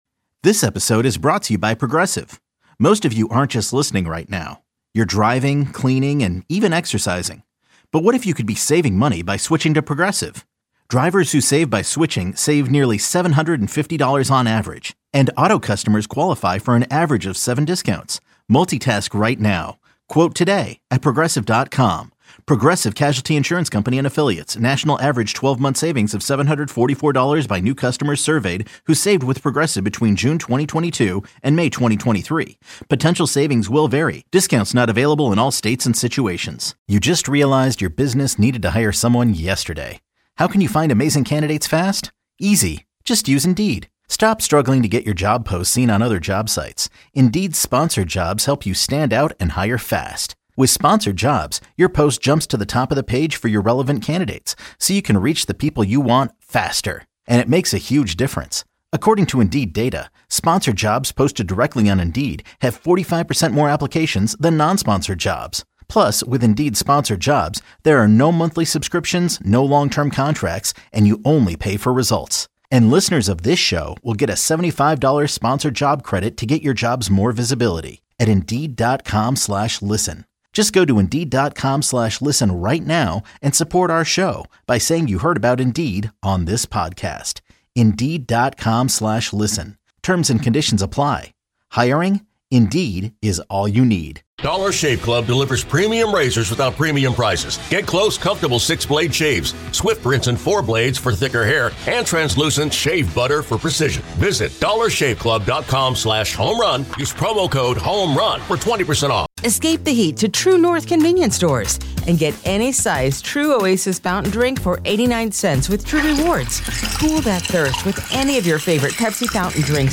Plus, an exclusive interview with Head Coach Nick Sirianni!